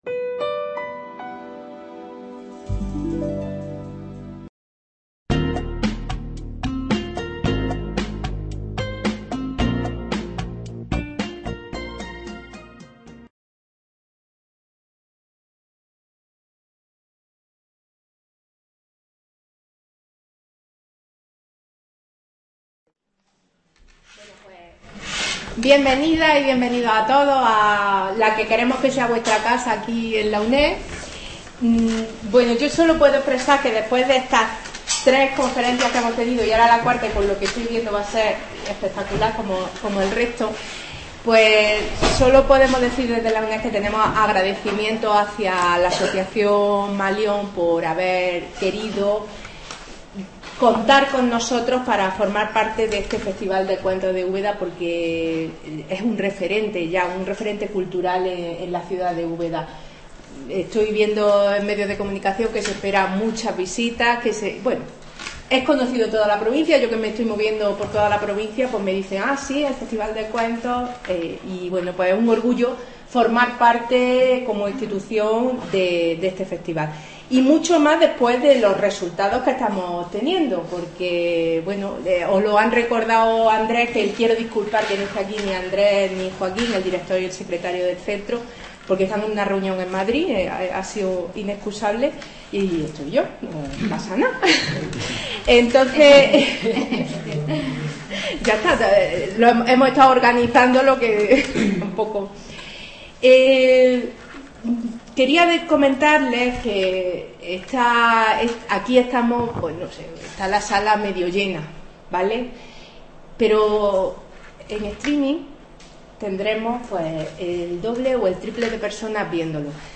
conferencia
del XX Festival de cuentos en Úbeda se cuenta (2019)